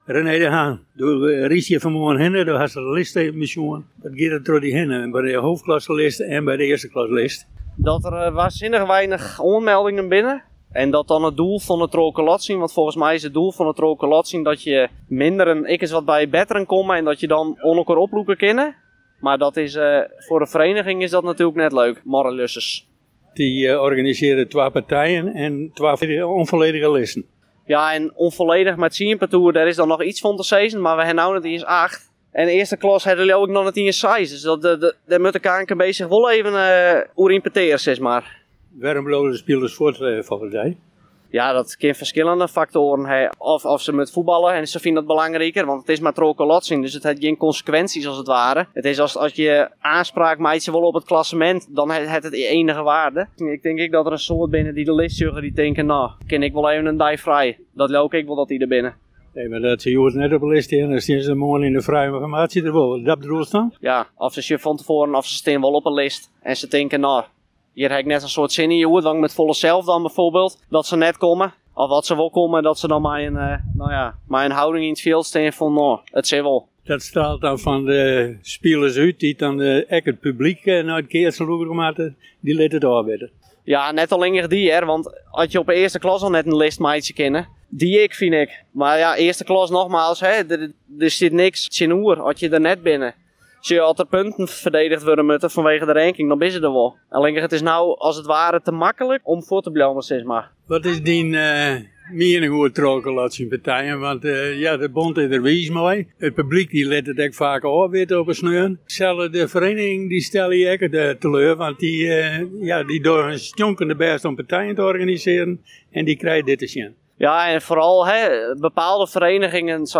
Yn petear